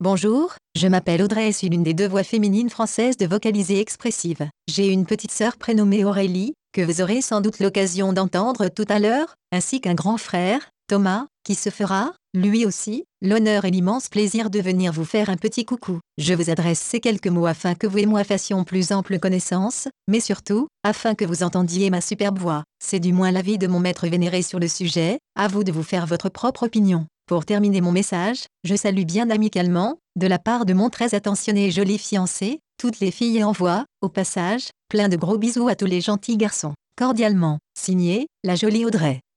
Texte de démonstration lu par Audrey, voix féminine française de Vocalizer Expressive
Écouter la démonstration d'Audrey, voix féminine française de Vocalizer Expressive